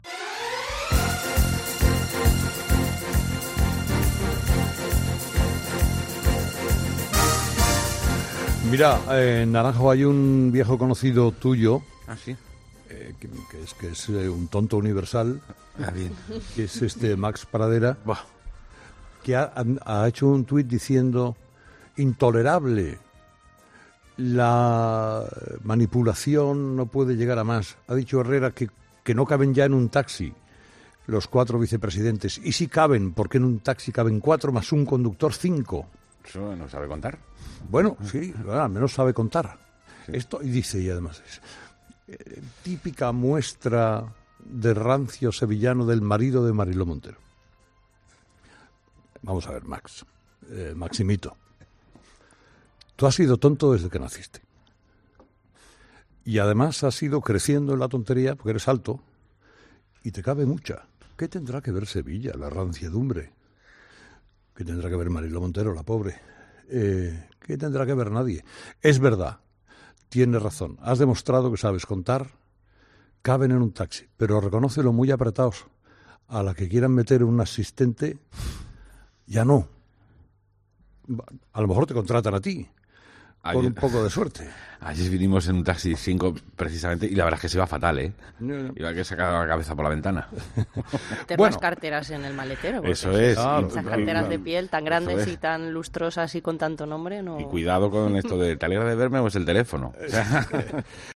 Así responde en directo Herrera a un ataque de Máximo Pradera